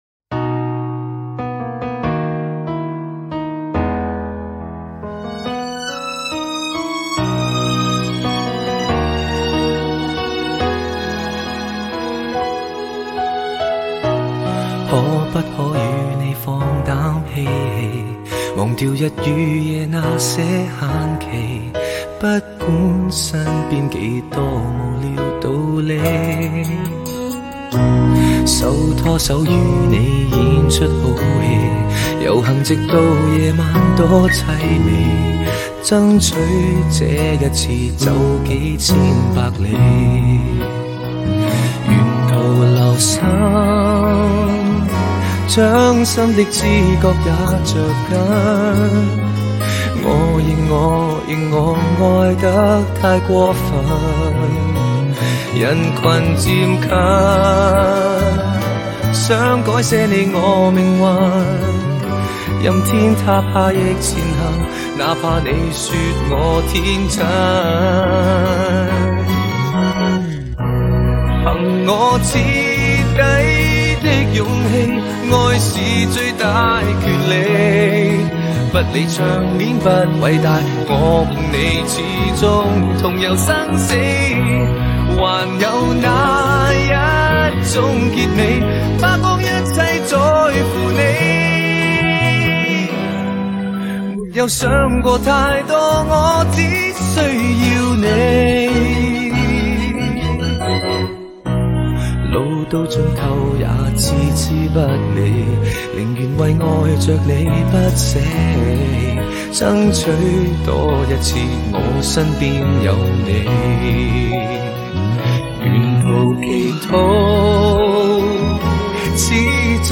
经典歌曲